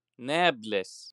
Nablus (AFI: /ˈnablus/;[1] in arabo نابلس?, Nābulus, pronuncia palestinese: [ˈnæːblɪs]